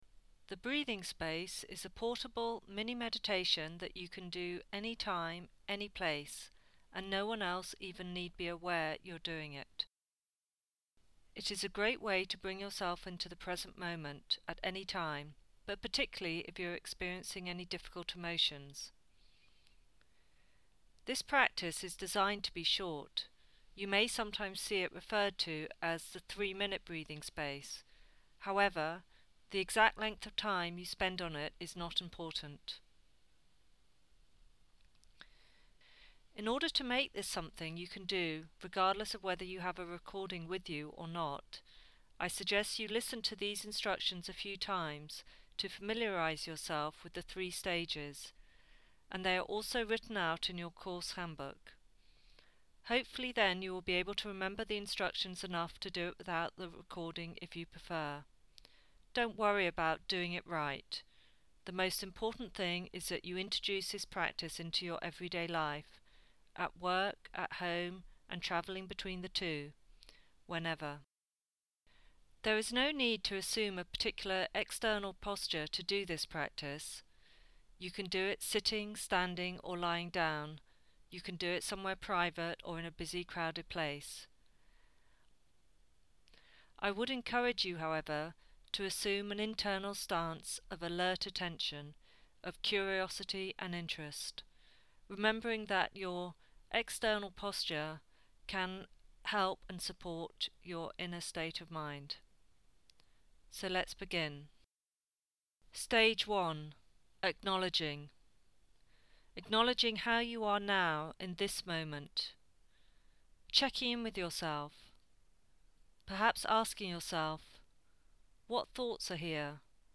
This free meditation is an opportunity for you to try a guided meditation practice.
GUIDED THREE-STEP BREATHING SPACE